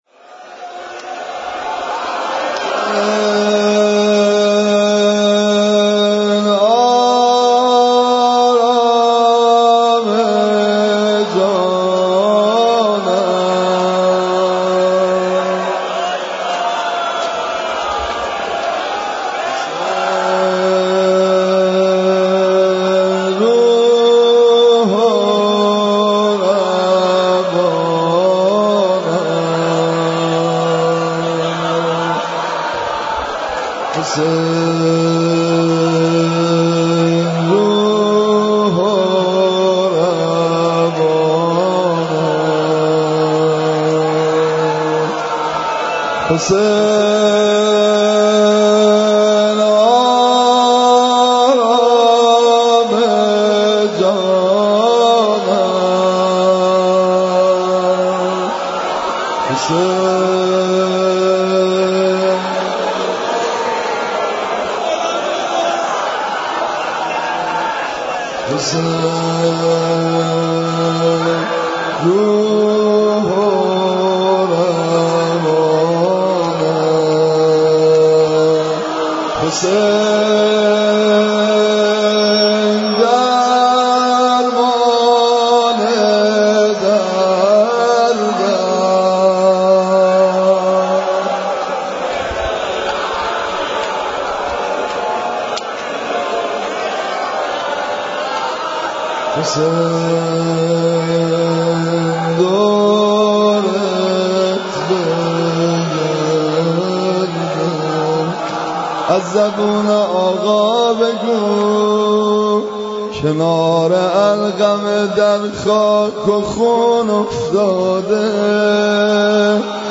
روضه حضرت عباس (ع)